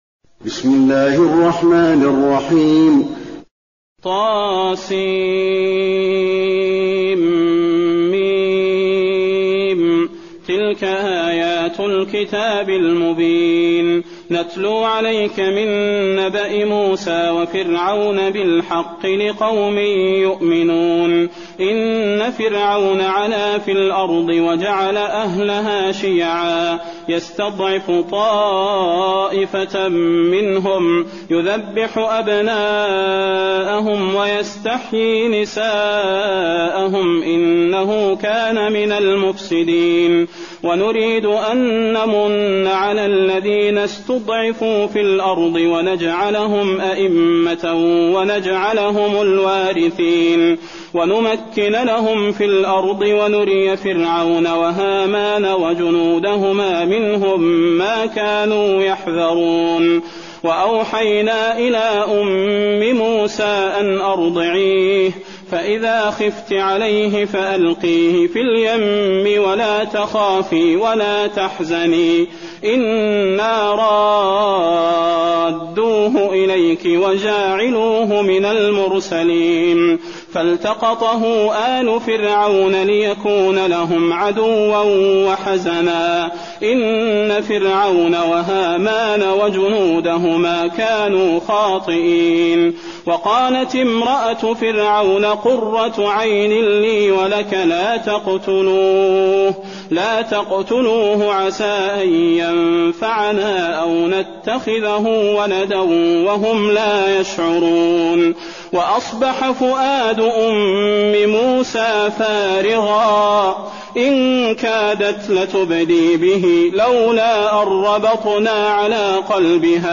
المكان: المسجد النبوي القصص